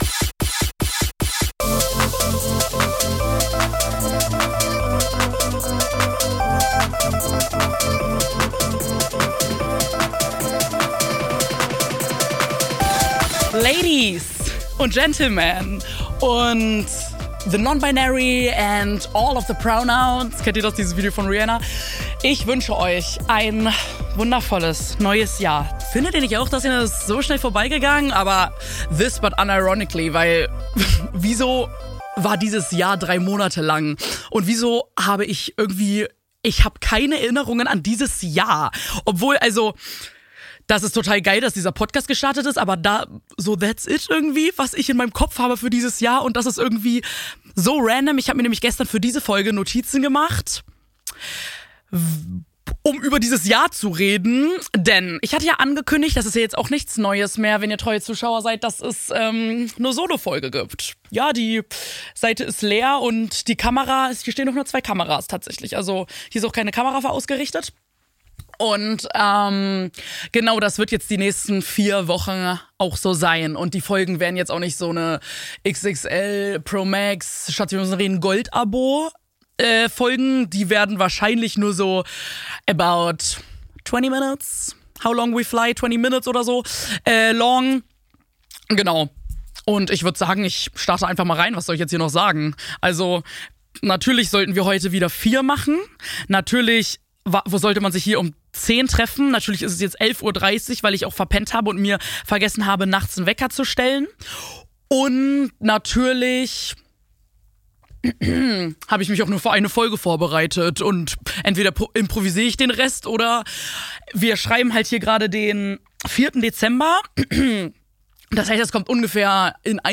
Hallihallo, meine Lieben, frohes neues Jahr und herzlich willkommen zur ersten Solo-Folge dieses Podcasts!